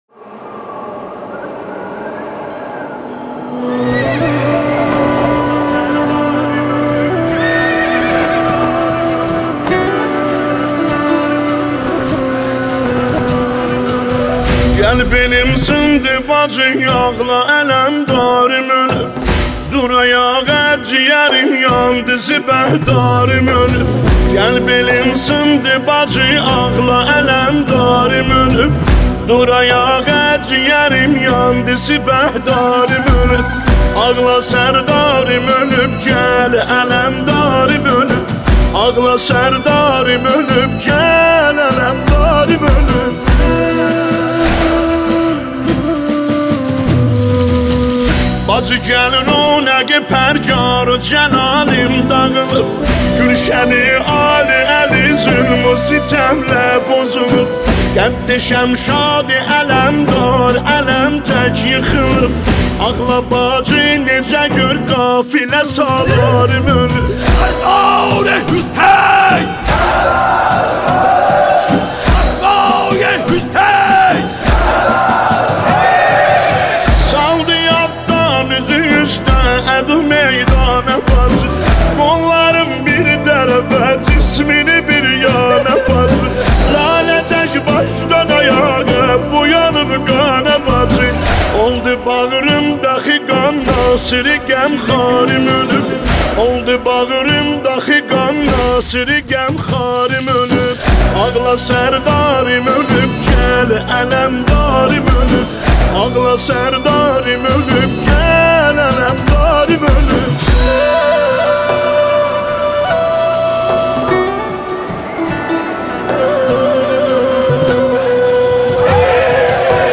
نوحه جدید